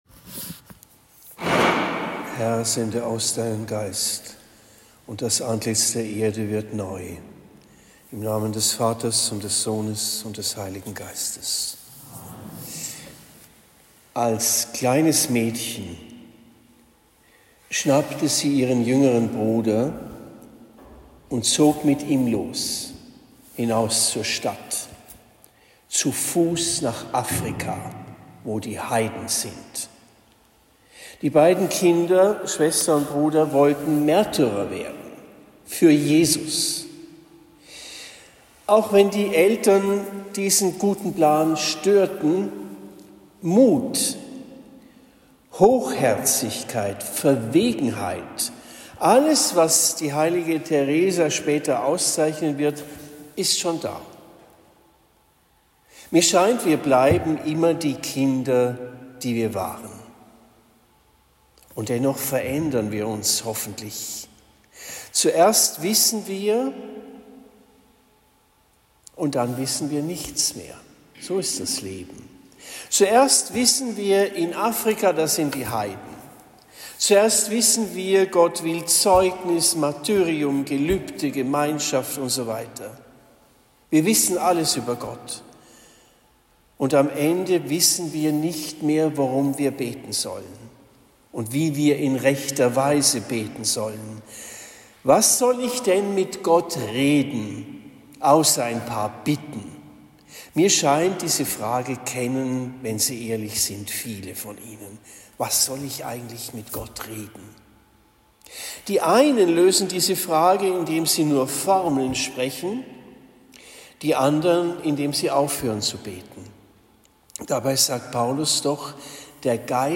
Predigt am 15. Oktober 2024 in Trennfeld